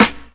• Puffy Steel Snare Drum Sample C# Key 21.wav
Royality free snare sound tuned to the C# note. Loudest frequency: 1564Hz
puffy-steel-snare-drum-sample-c-sharp-key-21-5df.wav